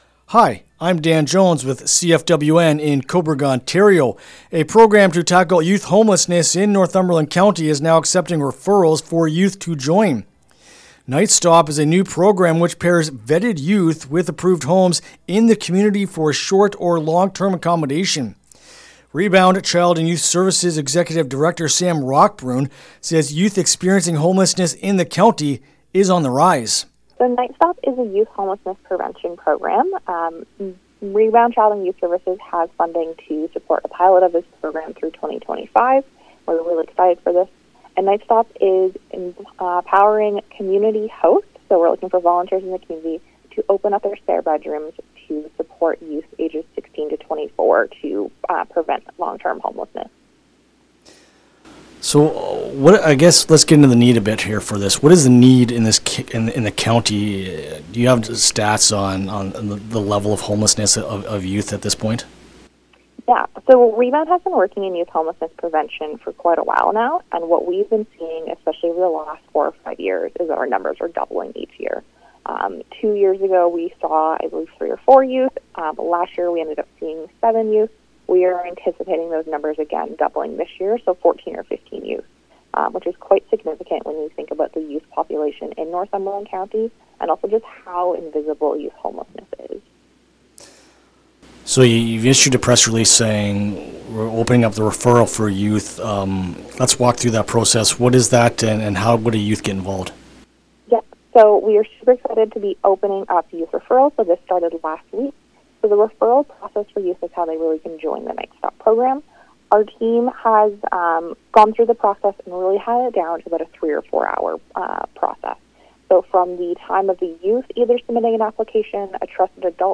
Nightstop-Youth-Referral-Interview-LJI.mp3